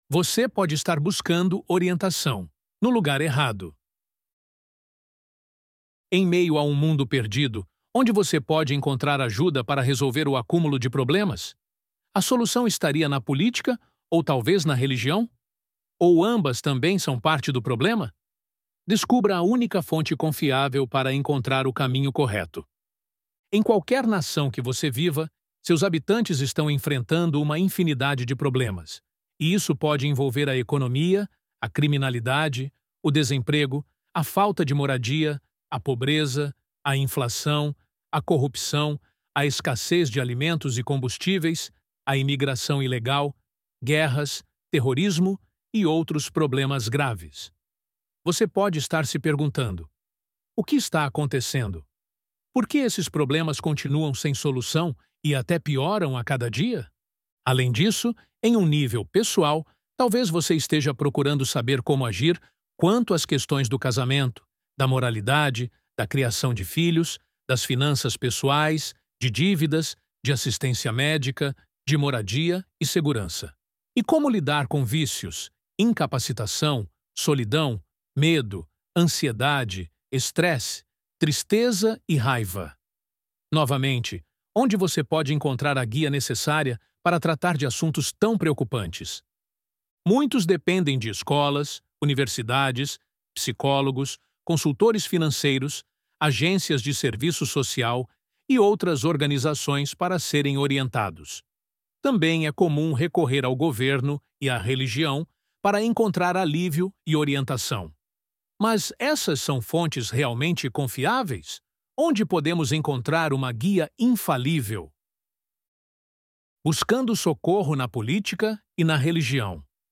ElevenLabs_Você_Pode_Estar_Buscando_Orientação_No_Lugar_Errado.mp3